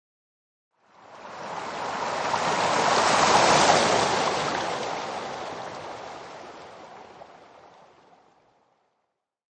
waves.mp3